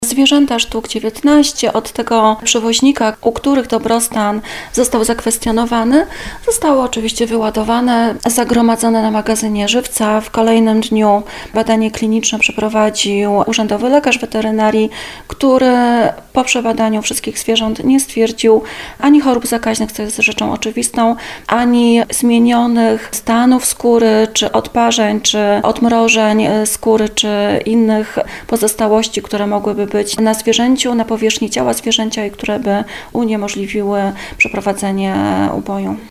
Zastępca Powiatowego Lekarza Weterynarii w Tarnowie Agnieszka Szewczyk-Kuta przyznaje, że zwierzęta zostały przebadane, ale kontrola niczego nie wykazała.